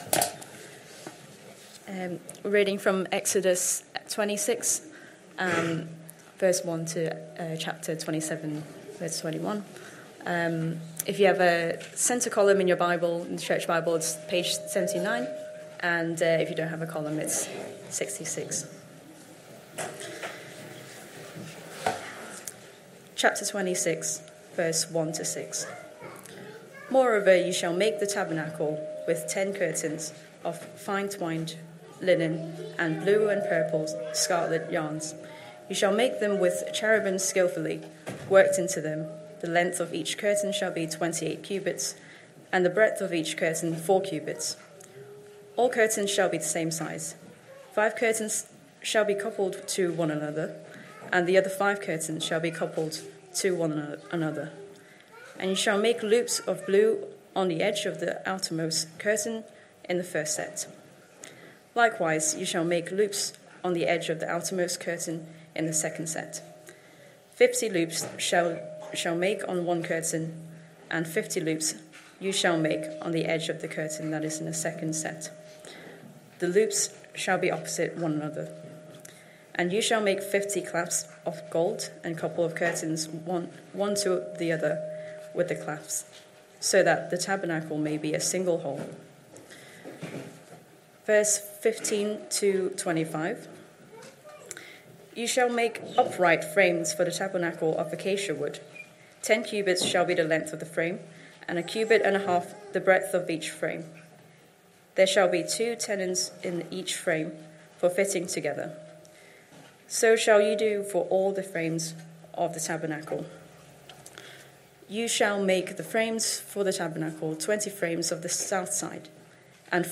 Christ Church Sermon Archive